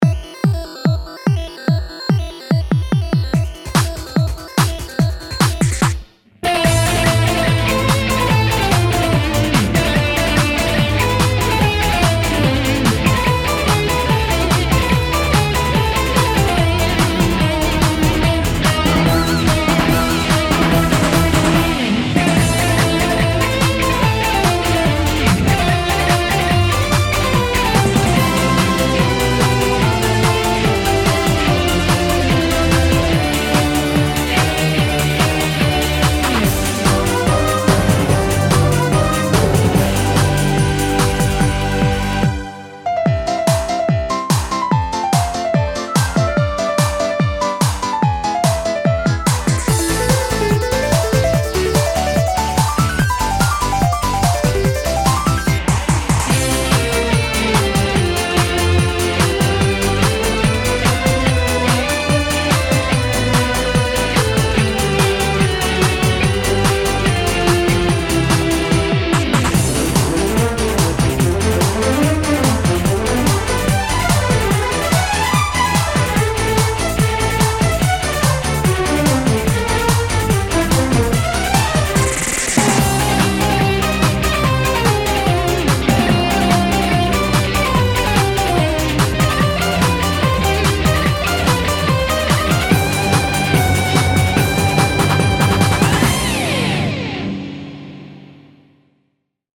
BPM145
Audio QualityPerfect (High Quality)
a song with a high difficulty but a good rhythm